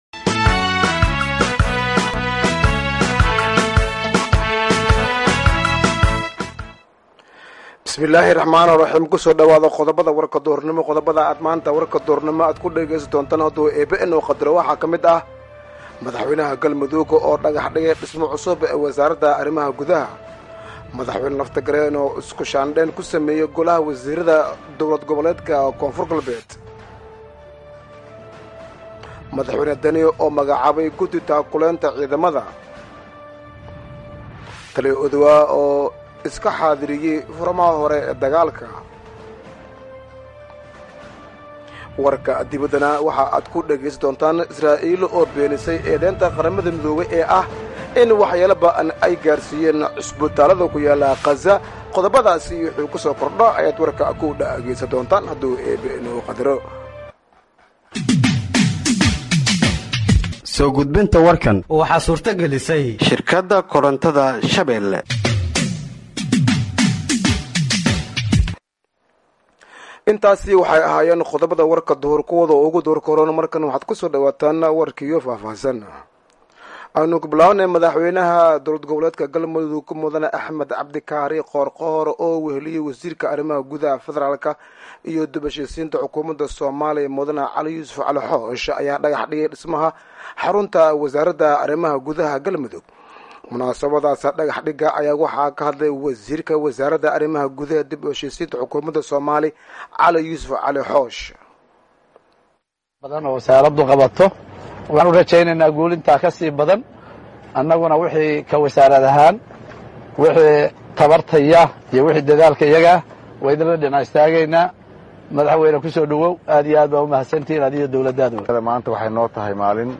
Dhageeyso Warka Duhurnimo ee Radiojowhar 01/01/2025